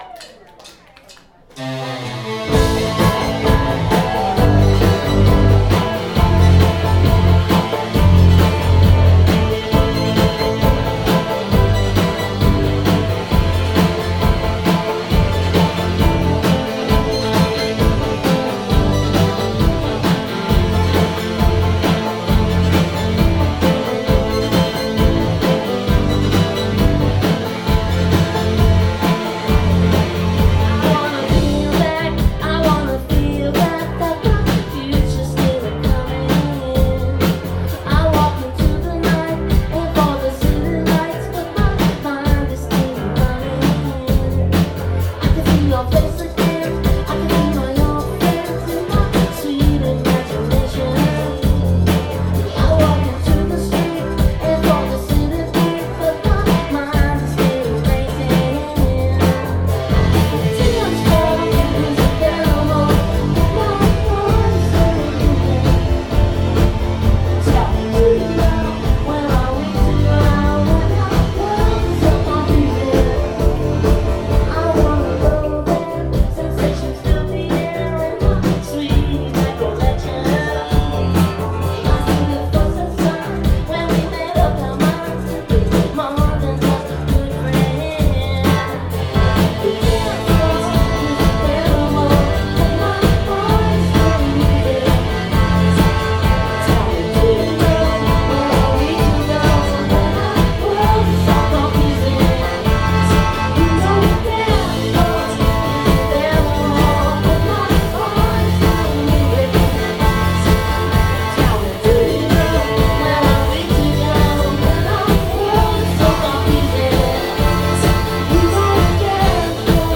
Here is a live version of a song off of the new album.